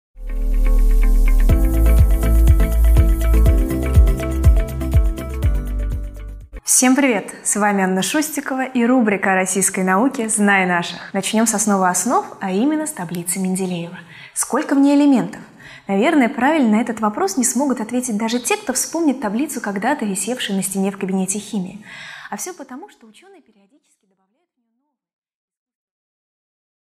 Аудиокнига О новых элементах стволовых клетках и белке для роста головы | Библиотека аудиокниг